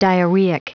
Prononciation du mot diarrheic en anglais (fichier audio)
diarrheic.wav